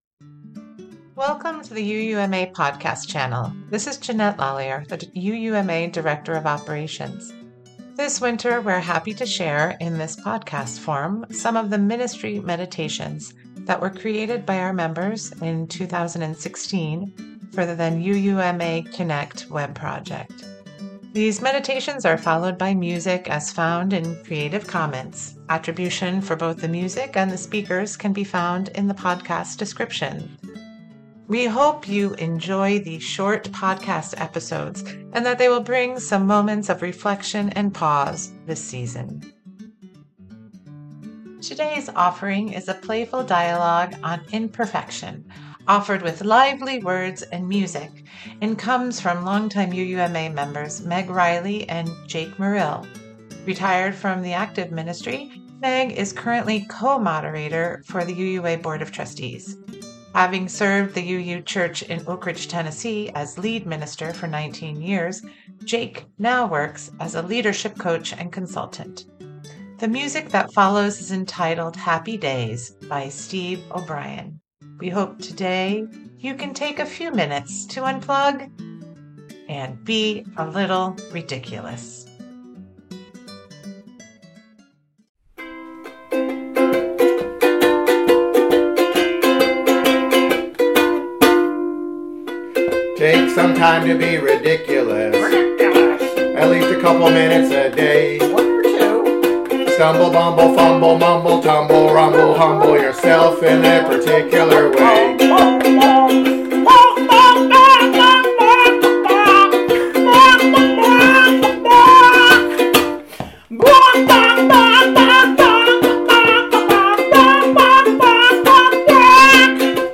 This winter we are happy to share, in podcast form, some of the Ministry Meditations as created by our members in 2016 for the UUMA Connect web project. These meditations are followed by music as found in creative commons.